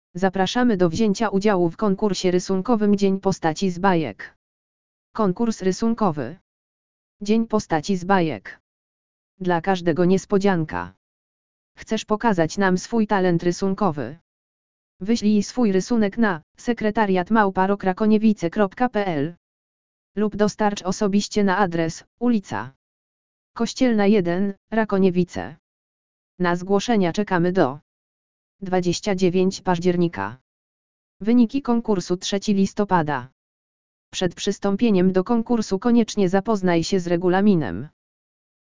audio_lektor_konkurs_rysunkowy_dzien_postaci_z_bajek.mp3